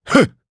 Lusikiel-Vox_Attack2_jp.wav